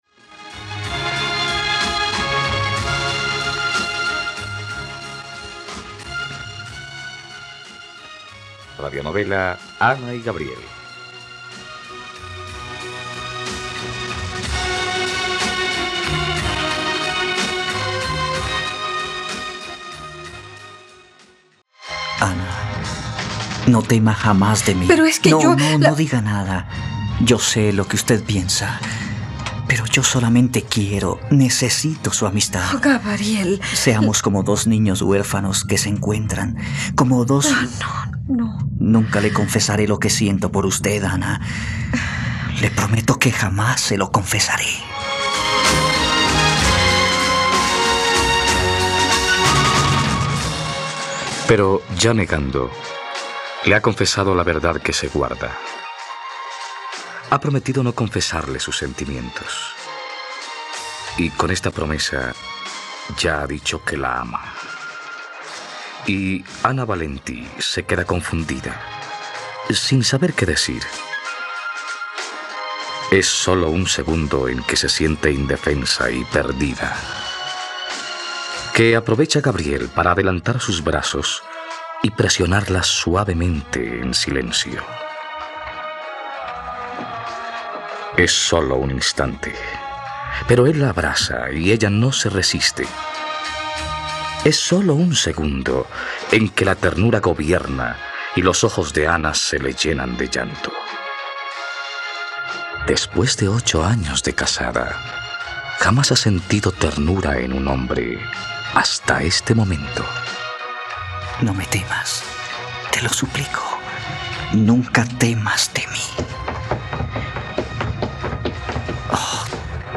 ..Radionovela. Escucha ahora el capítulo 32 de la historia de amor de Ana y Gabriel en la plataforma de streaming de los colombianos: RTVCPlay.